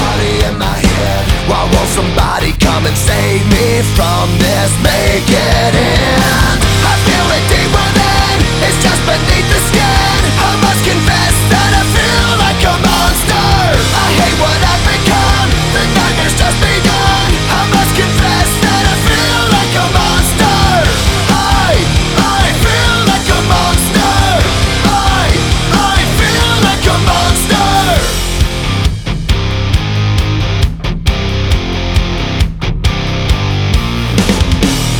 Жанр: Рок / Альтернатива / Пост-хардкор / Хард-рок